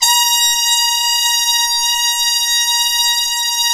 Index of /90_sSampleCDs/Roland LCDP06 Brass Sections/BRS_Harmon Sect/BRS_Harmon Tps S